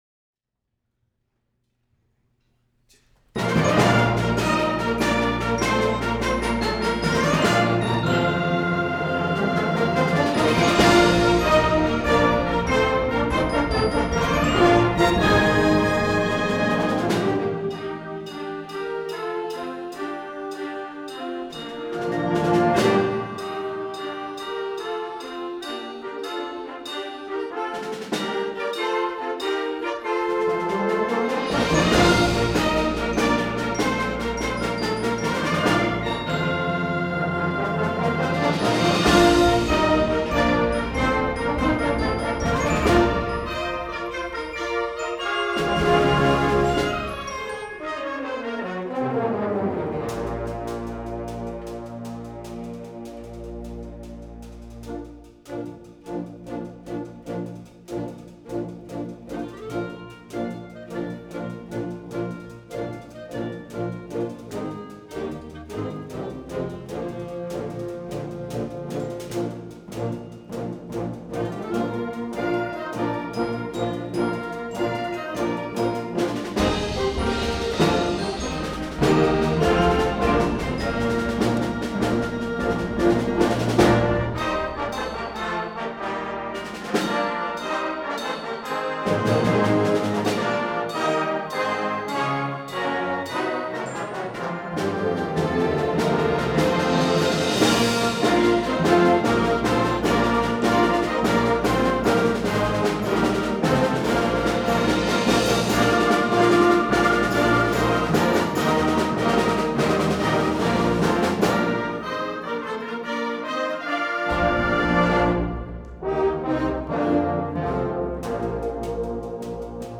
SBCC Concert Band Concert Recording - December 6, 2009